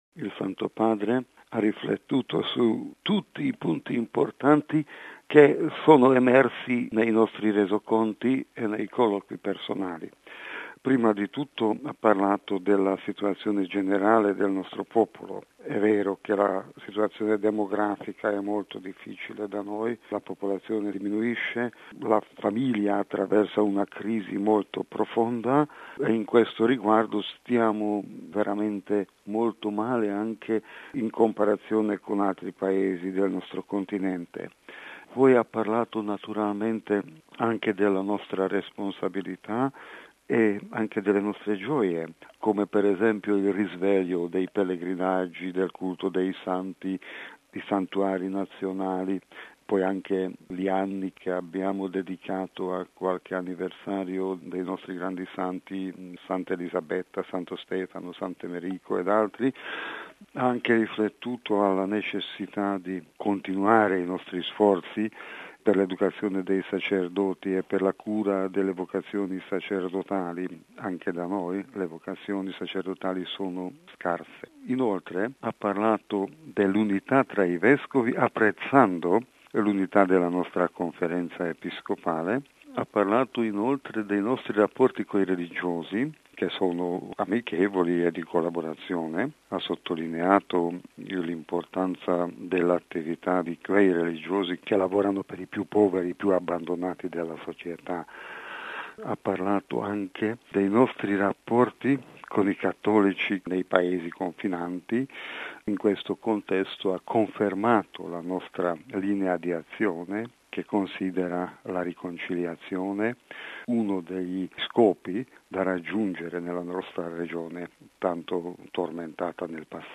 I vescovi ungheresi concludono la visita ad Limina: intervista con il cardinale Péter Erdö